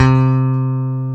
Index of /90_sSampleCDs/Roland - Rhythm Section/BS _E.Bass 2/BS _Rock Bass
BS  ROCKBSC4.wav